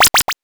powerup_19.wav